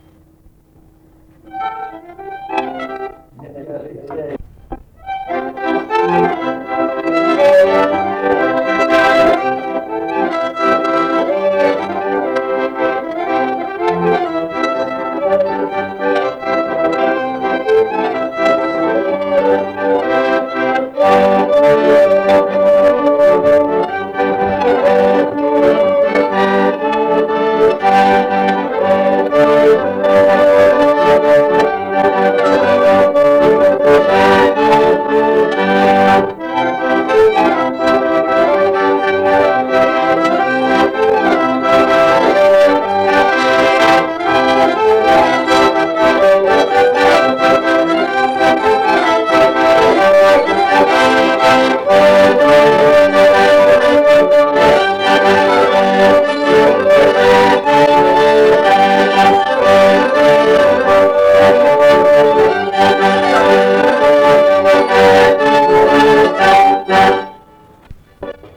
instrumentinis
akordeonas
smuikas
Polka